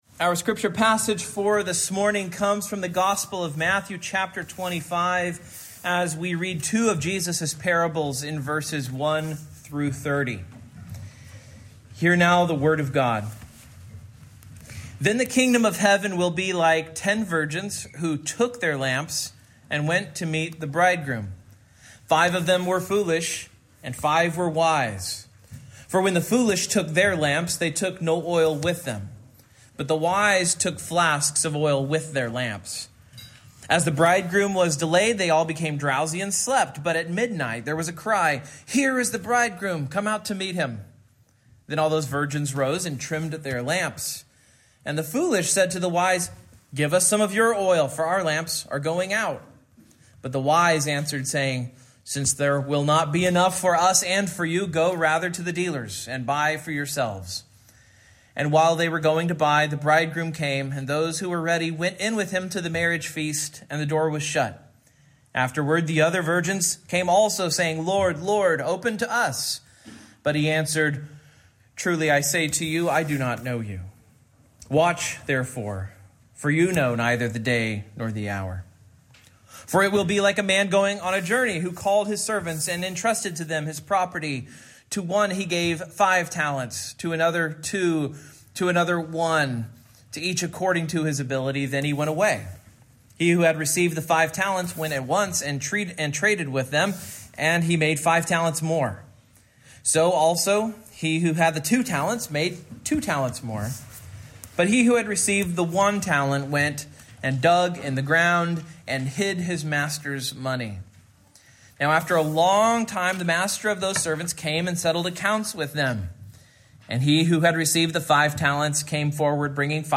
Matthew 25:1-30 Service Type: Morning Main Point